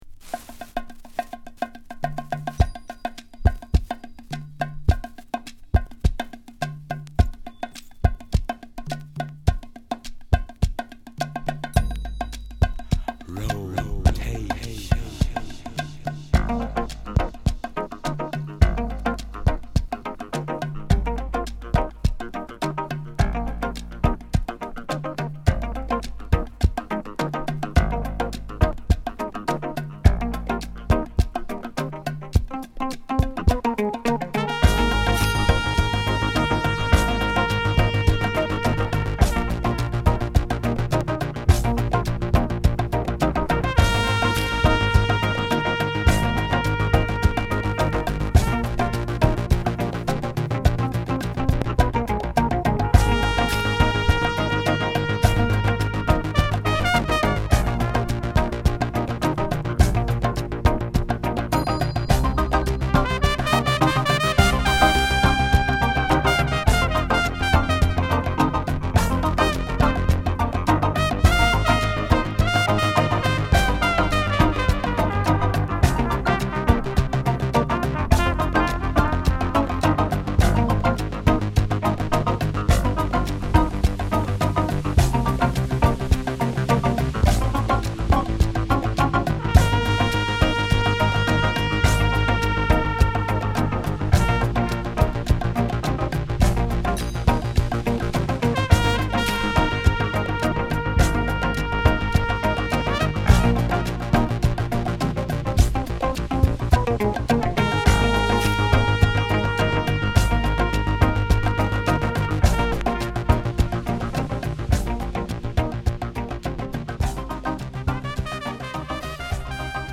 ウニョウニョシンセ音に乘せた哀愁トランペットが突き抜けてる…。壮大な世界観に思わず陶酔！